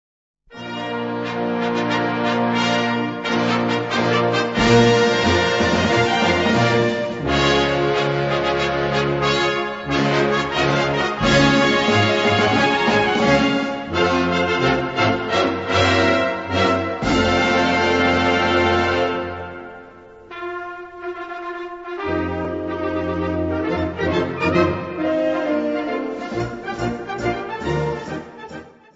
Gattung: Konzertstück
A4 Besetzung: Blasorchester Zu hören auf